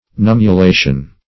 Search Result for " nummulation" : The Collaborative International Dictionary of English v.0.48: Nummulation \Num`mu*la"tion\, n. (Physiol.)